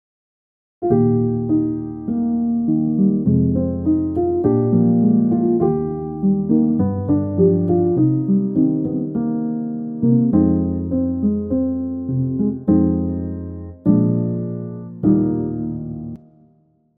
Звуки ожидания
Звук предвкушения романтической встречи